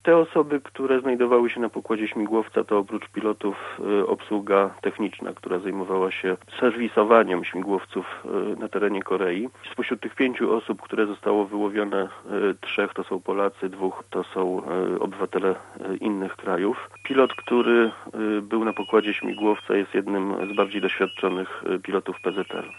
Mówi rzecznik